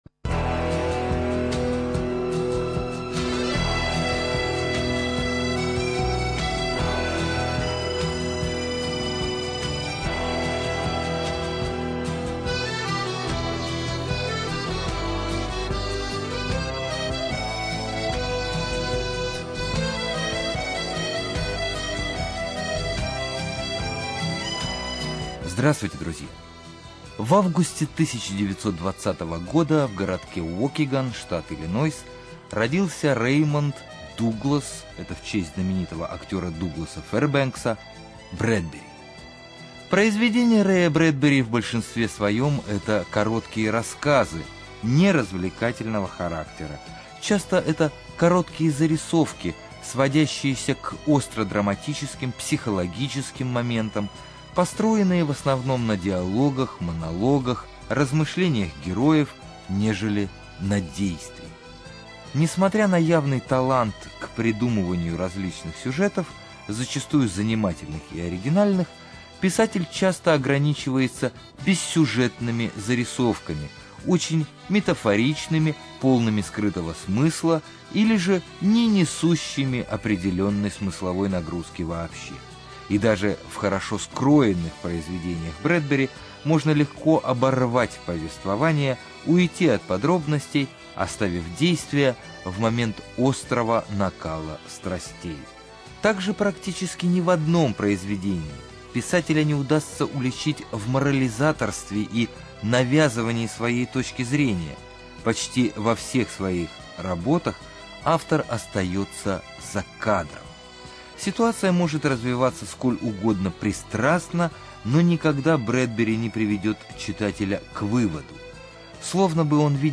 Студия звукозаписиРадио 4